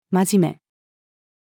真面目-female.mp3